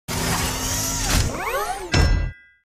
Play, download and share homem de ferro som original sound button!!!!
iron-man-suit-up-sound-effects-free.mp3